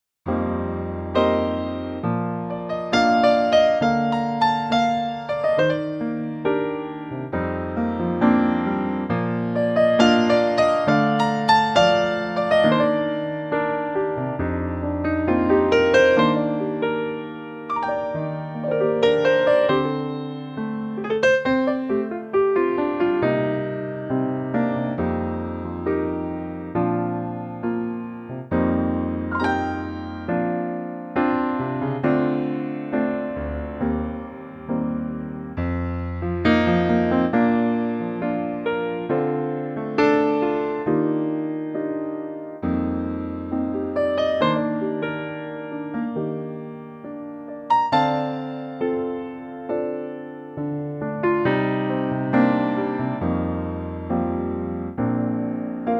key - Bb - vocal range - G to Bb (optional C)
A gorgeous piano only arrangement